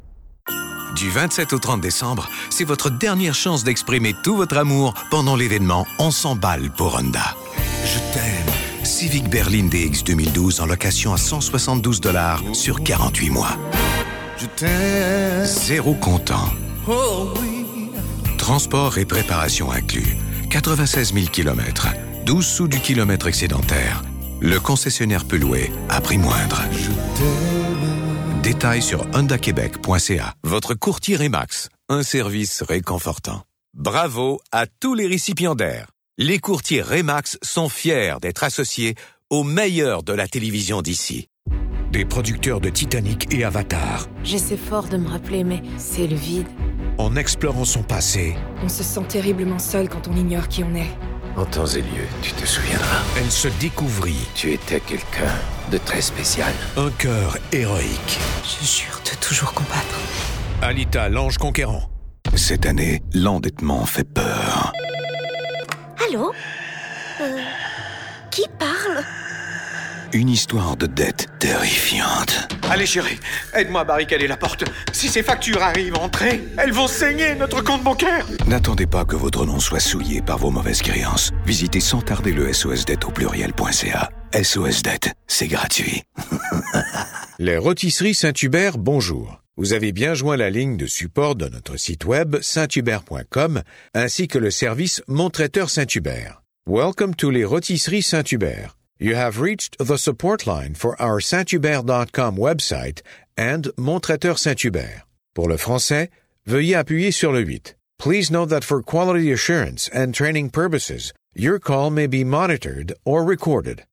Mature French Canadian VO Artist
Various demo
Middle Aged
Senior